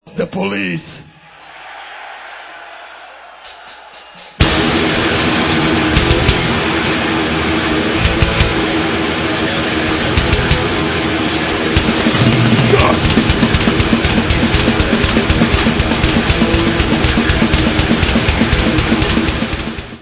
sledovat novinky v oddělení Rock - Speed/Thrash/Death Metal